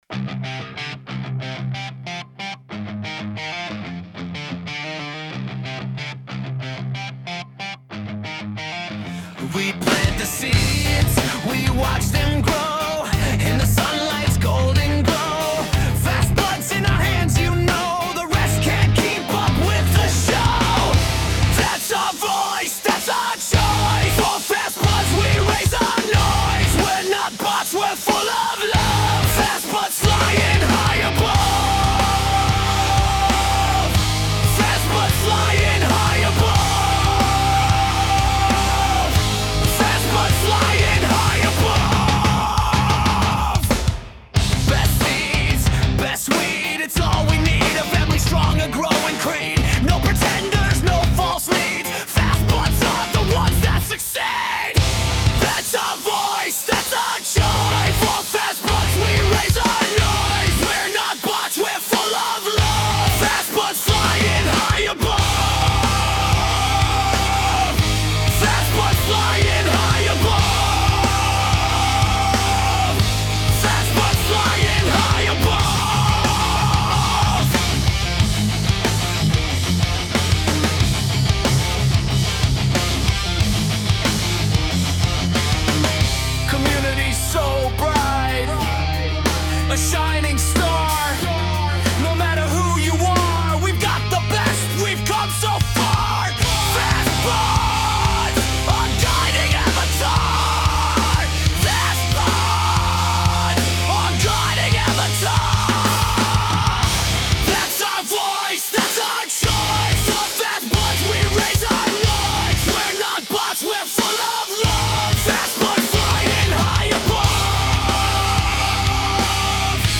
metal 17 Dec 2025